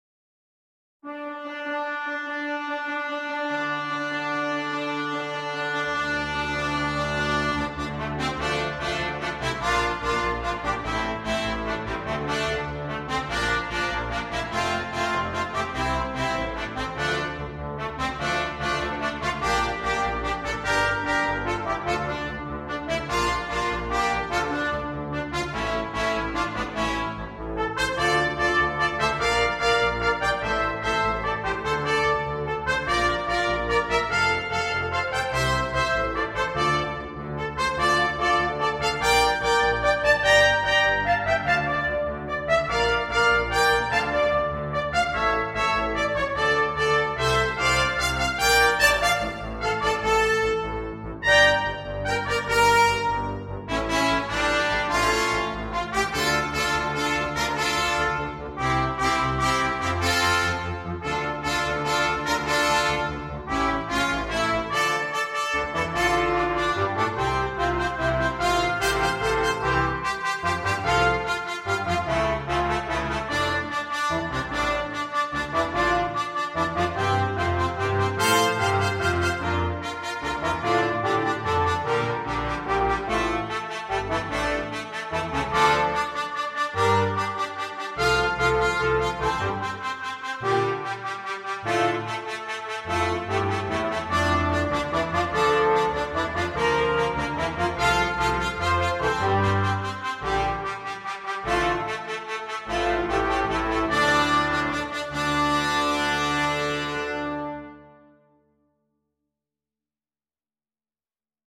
для брасс-квинтета